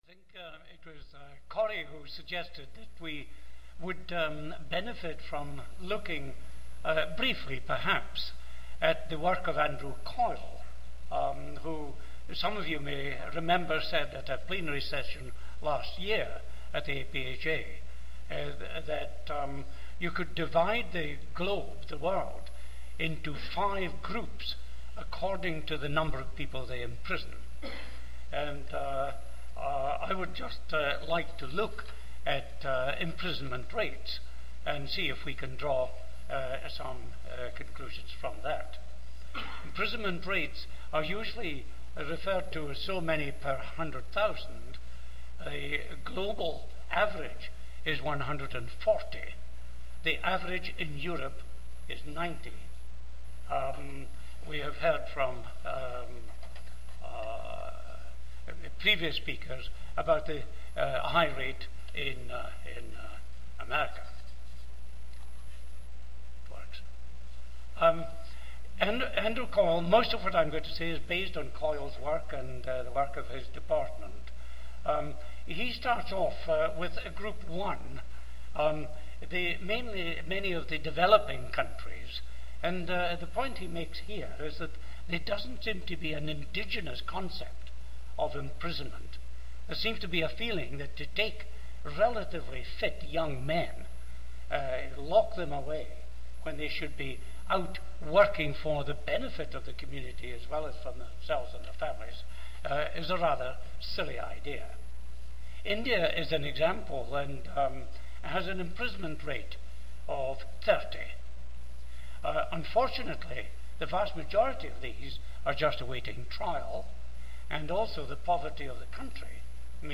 This session will explore from an international perspective various ways societies use prisons. Members of the WHO Health in Prison Project will illustrate important strategies for prison reform including some of the best practice solutions to the problems of infectious disease, mental health, rehabilitation and community resettlement, as well as policy development and implementation throughout greater Europe.